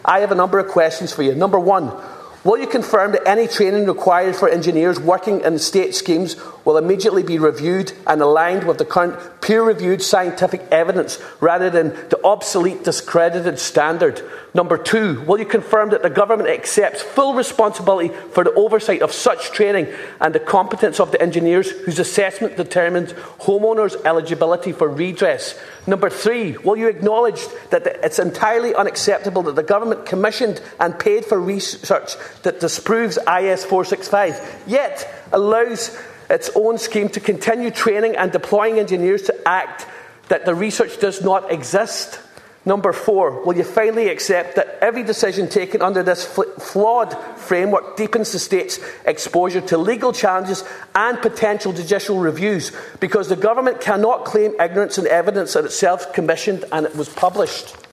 The controversial training conducted by Engineers Ireland on the outdated IS465 standard was raised in the Dáil.
Deputy Ward has put a number of questions to Simon Harris: